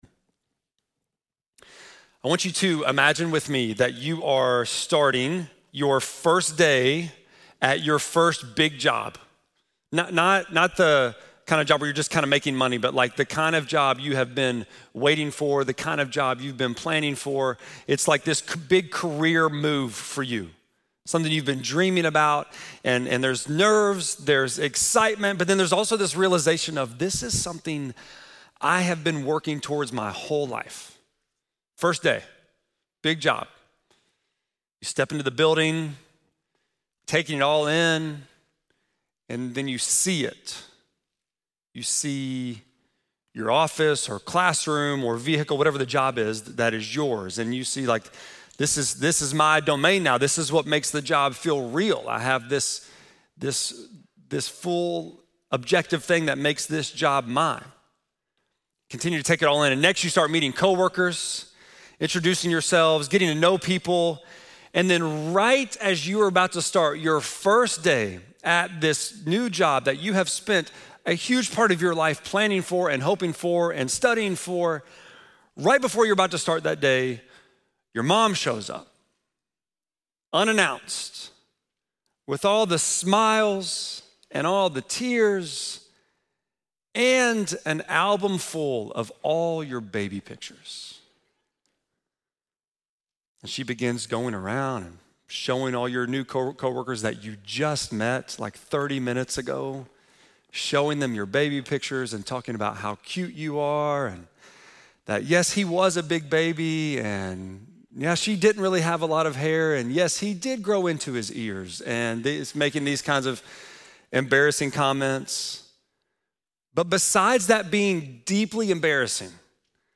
12.14-sermon.mp3